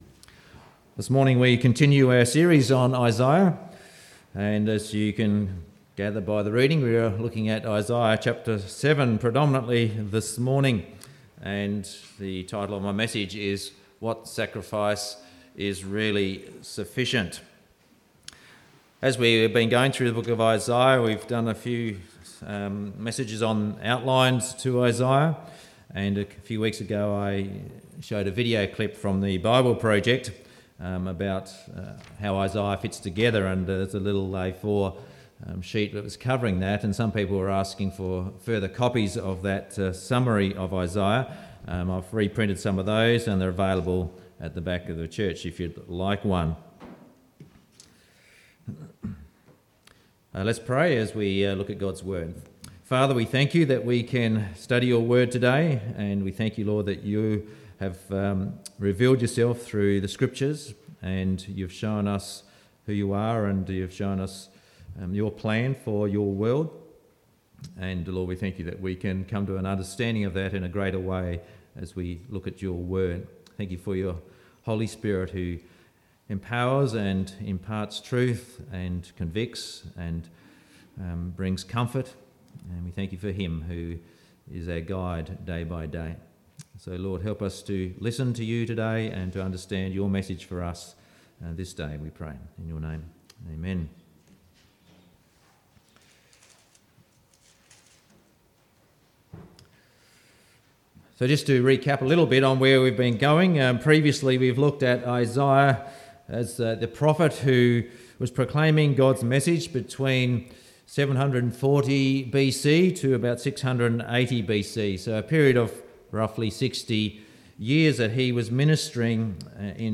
30.7.17-Sunday-Service-What-sacrifice-is-really-significant-Isaiah.mp3